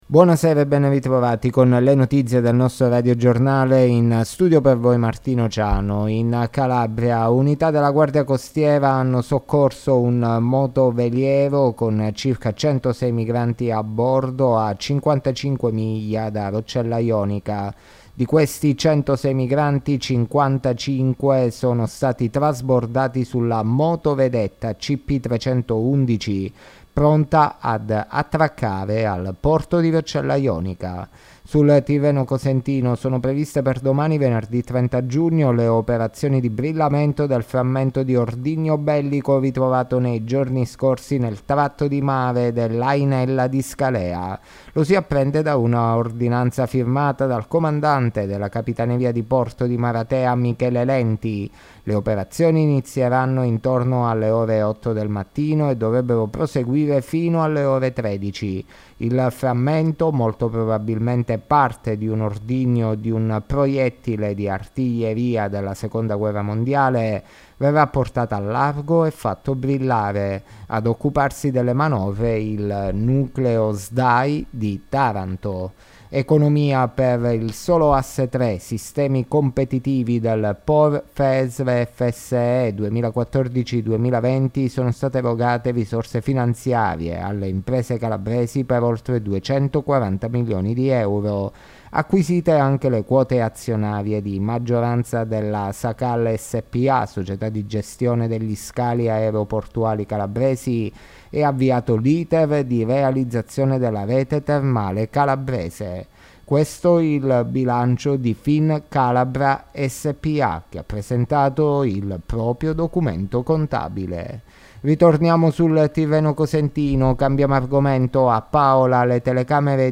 Le notizie della sera di Giovedì 29 Giugno 2023